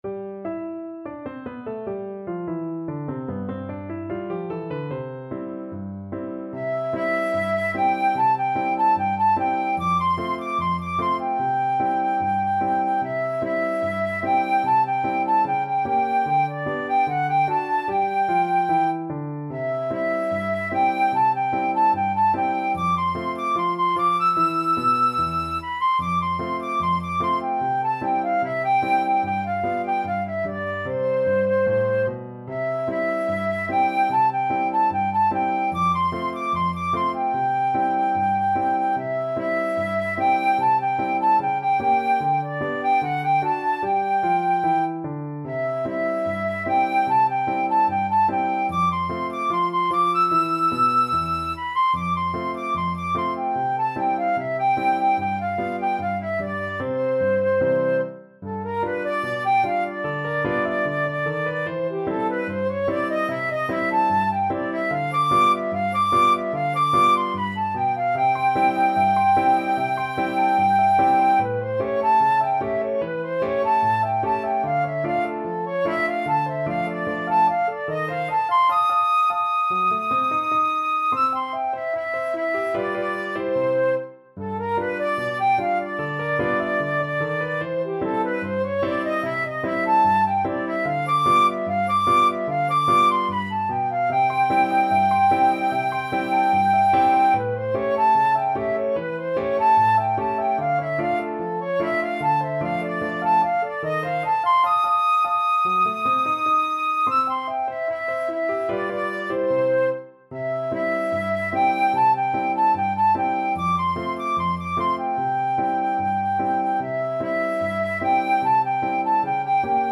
Jazz Scott Joplin The Easy Winners (A Rag Time Two Step) Flute version
Flute
C major (Sounding Pitch) (View more C major Music for Flute )
Not Fast = 74
2/4 (View more 2/4 Music)
Jazz (View more Jazz Flute Music)